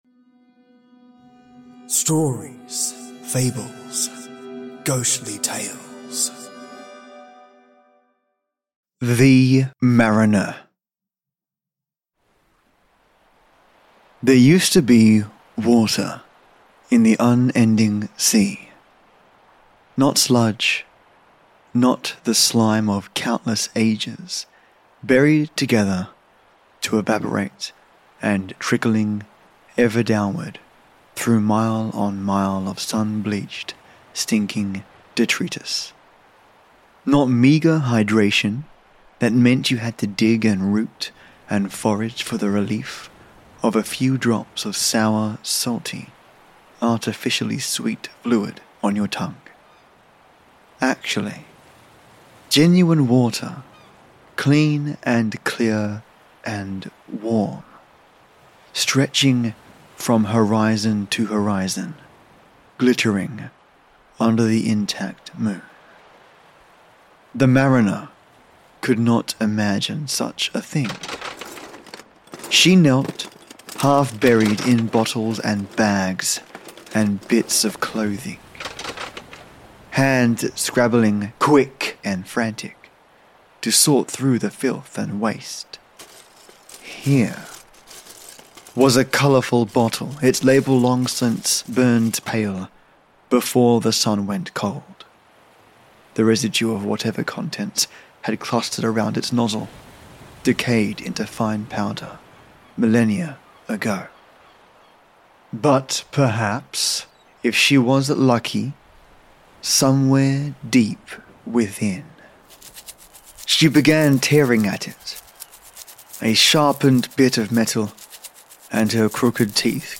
Today I'm going back to my roots and stress testing the new microphone some more 💛and I'm starting with two Creepypasta's - one about a post apocalyptic seaworld nightmare titled The Mariner, and the second tale is all about Fearing the Cold.....and embracing the frost to pay ...
This episode is also more an Audio Drama / Soundscape experience - focused on slow focus, and ease on the earballs, let me know what you think mates!